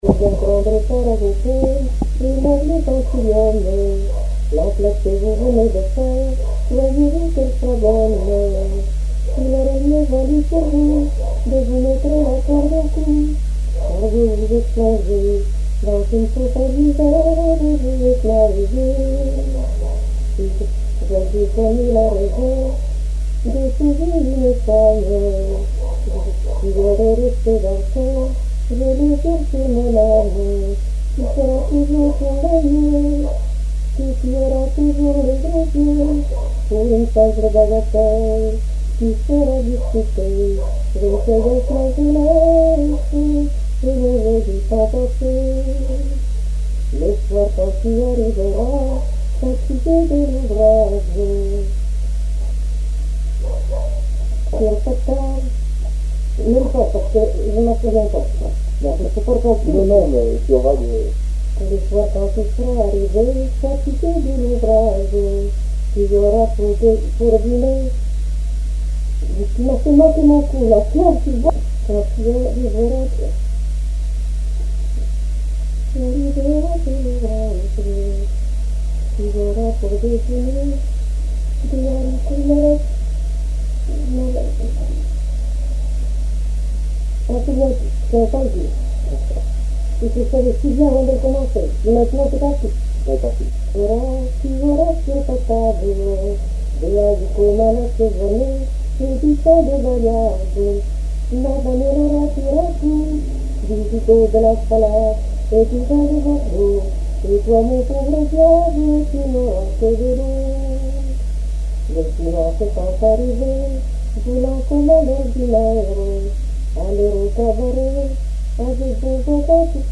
répertoire de chansons
Pièce musicale inédite